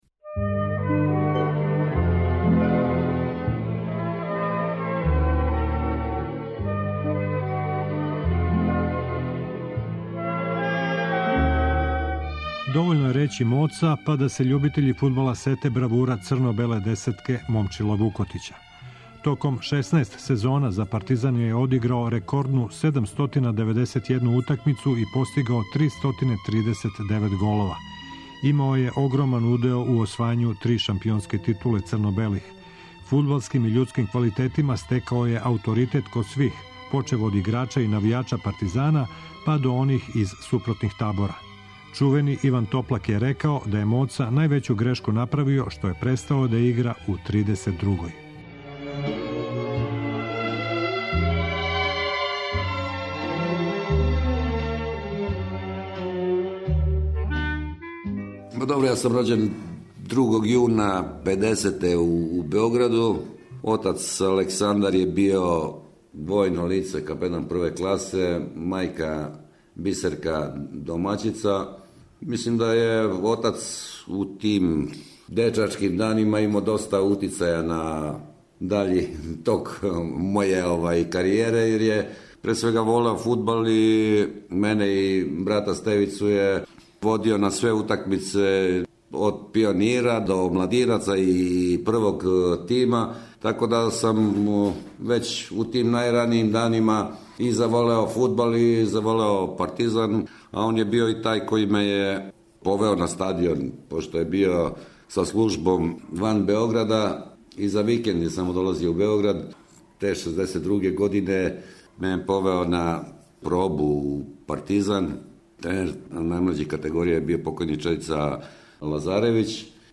Ове недеље, гост Спортског споменара је фудбалер Момчило – Моца Вукотић.
У емисији ће бити коришћени и инсерти из преноса значајних утакмица Момчила Вукотића: реч је о мечу са Олимпијом 1976. спектакуларном поготку против Црвене звезде, опроштајној утакмици пред више од 30 хиљада гледалаца.